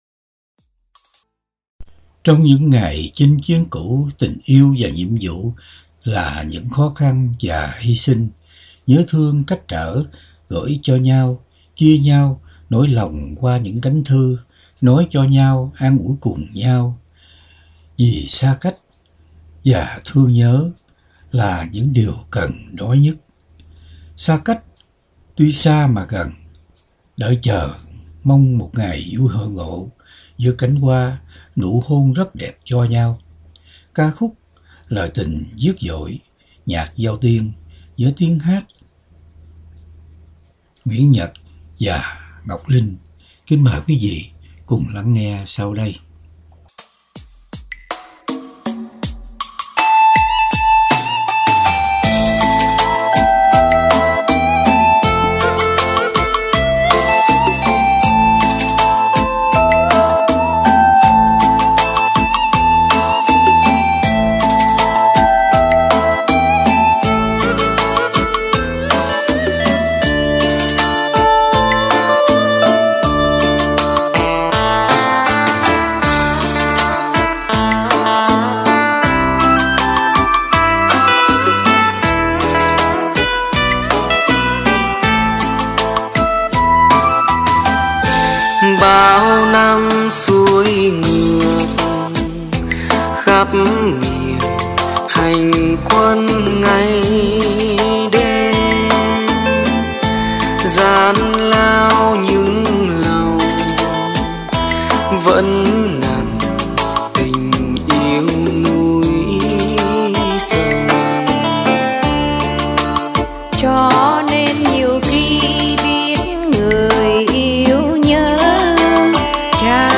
Âm Nhạc